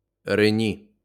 uttal(fil)) är en stad i sydvästra Ukraina som ligger vid Donau alldeles vid gränsen till Rumänien.